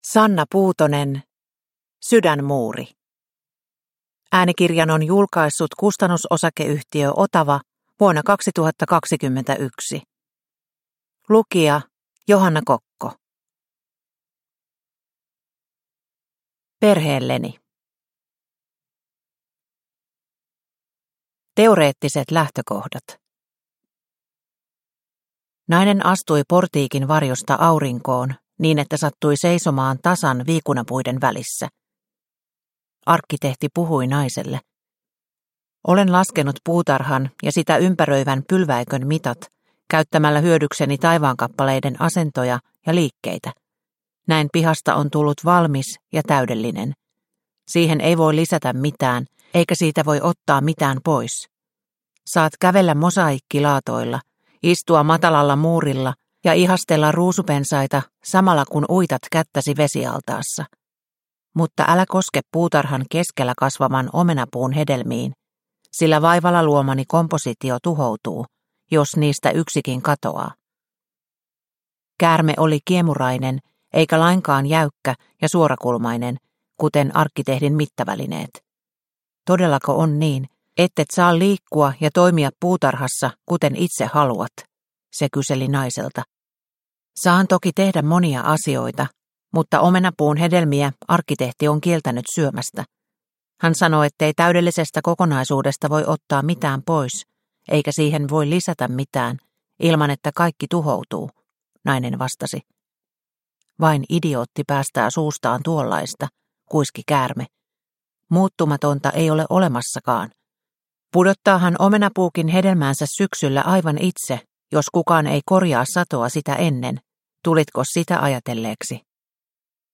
Sydänmuuri – Ljudbok – Laddas ner